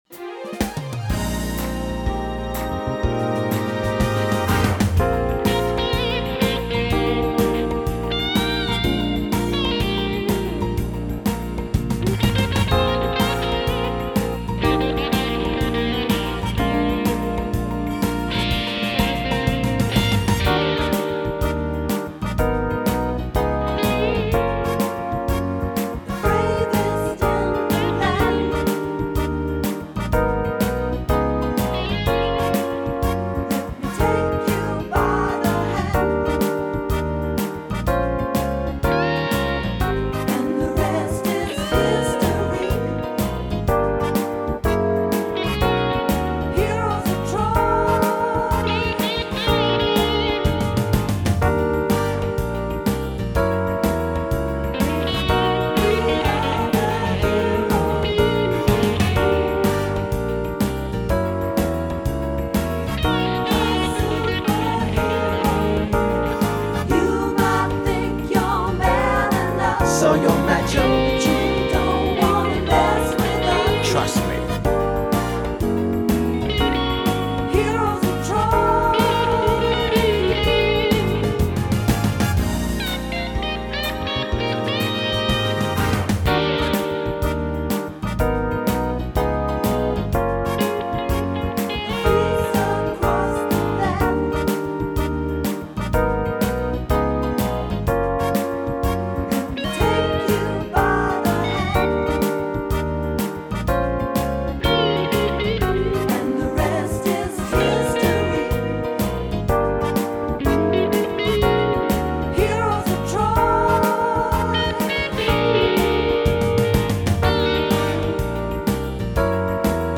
Song style: soul / swing
Sung by: The Greek winning side and chorus
Download the part vocal (harmony) version of the song